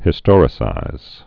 (hĭ-stôrĭ-sīz, -stŏr-)